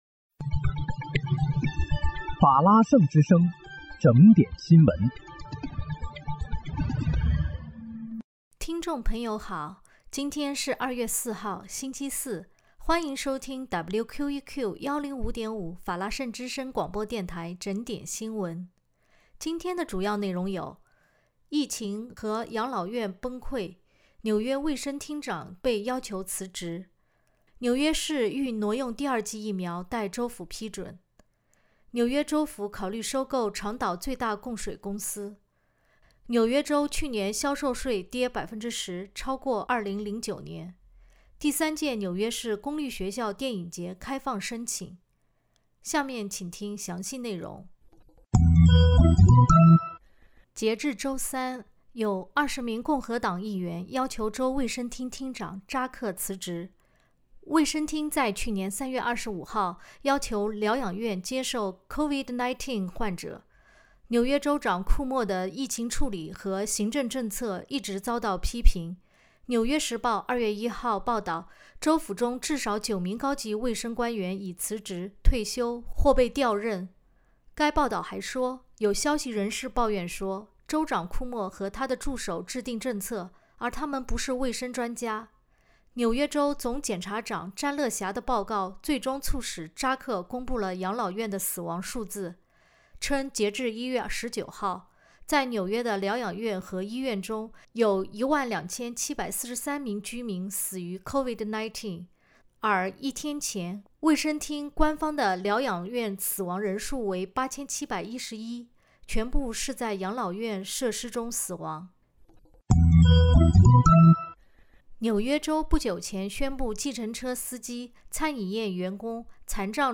2月4日（星期四）纽约整点新闻